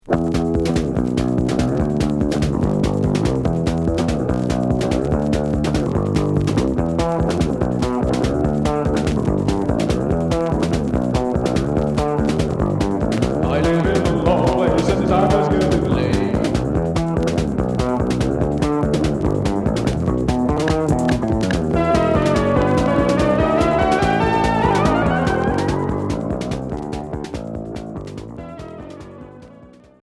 Cold wave